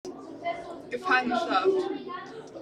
MS Wissenschaft @ Diverse Häfen